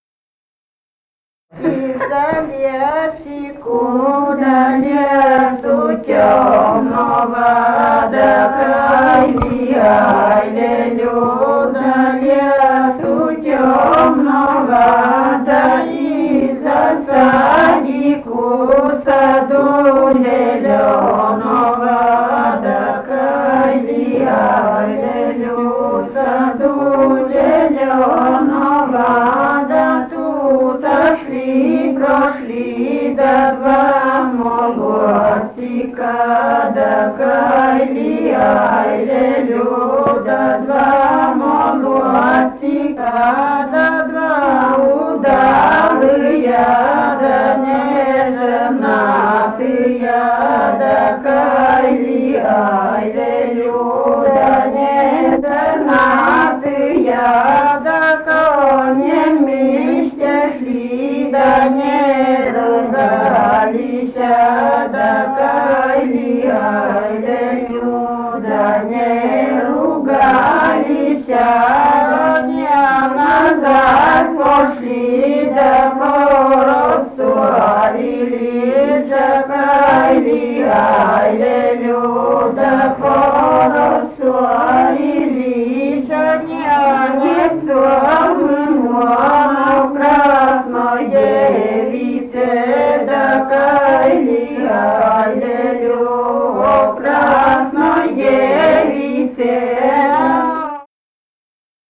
полевые материалы
Костромская область, д. Колодезная Межевского района, 1964 г. И0794-14